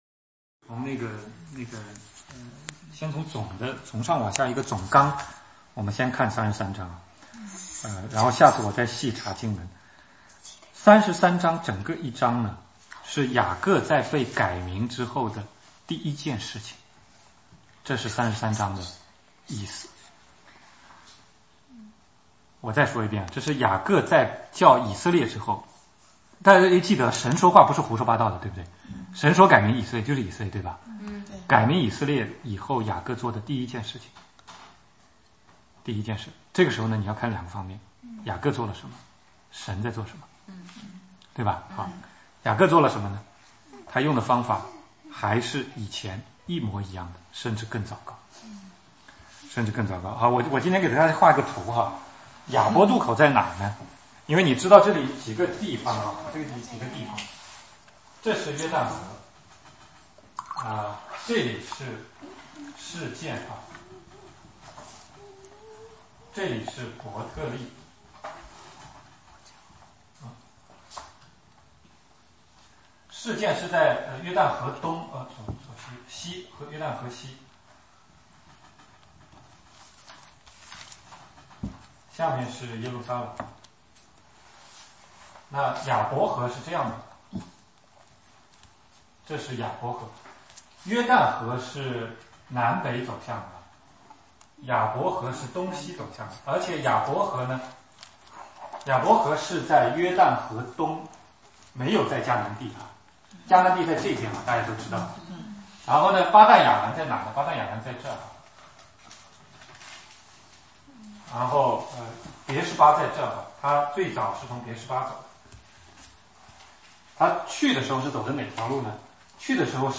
16街讲道录音 - 创世纪 神对雅各的带领2